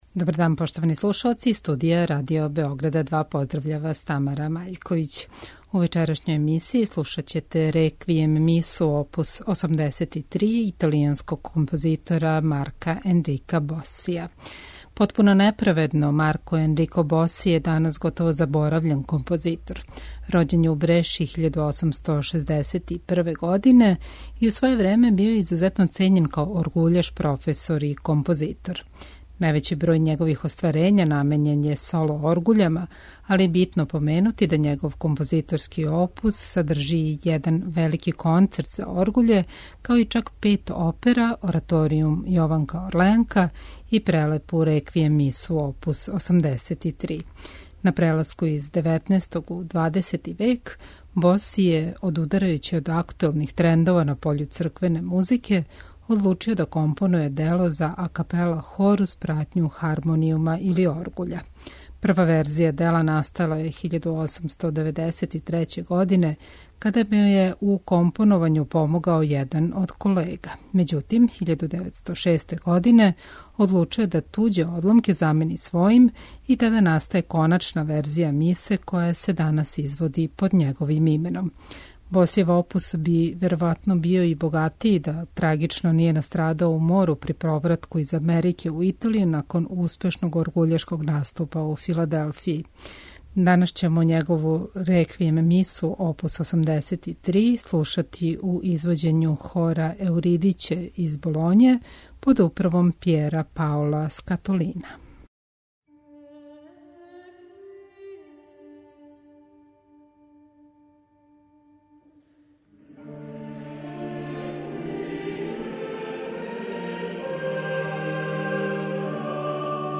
Ипак, компоновао је и пет опера, један велики ораторијум и узвишену Мису за мртве, коју ће у емисији извести хор „Еуридика" из Болоње.